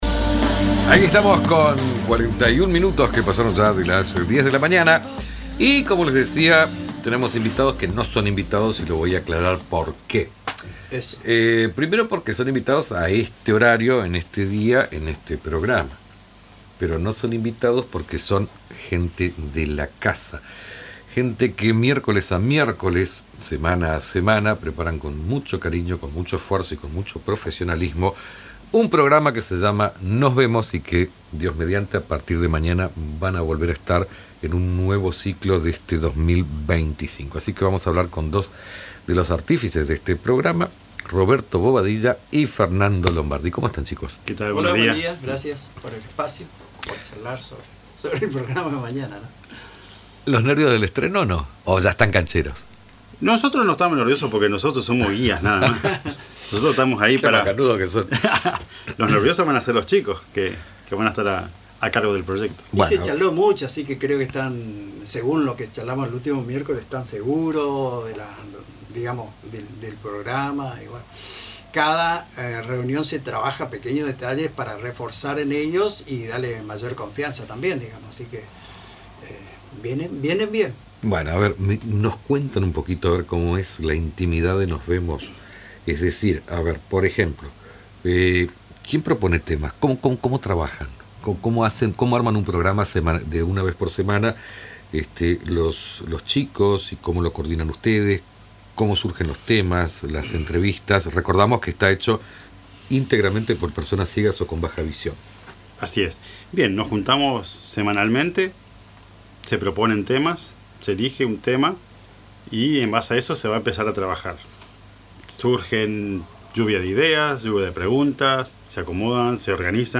En una entrevista exclusiva en Lo que faltaba